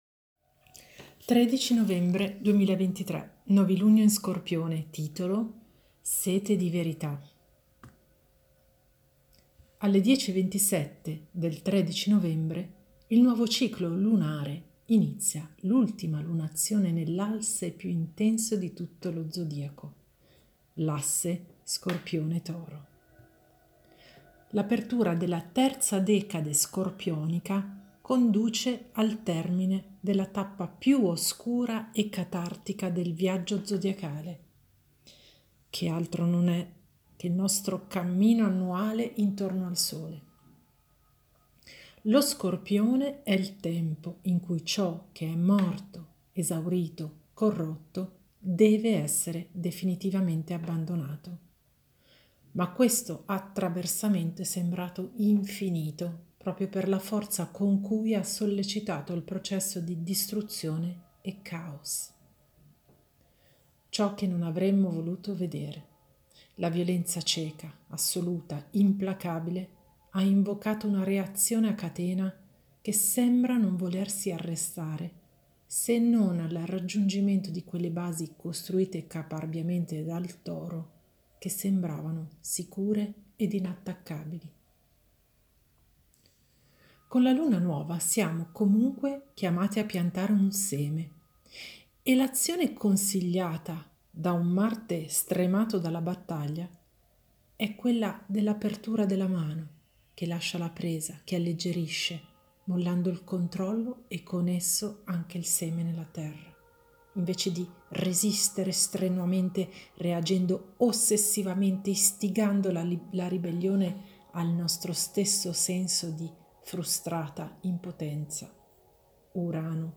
ASCOLTA l'articolo letto da me QUI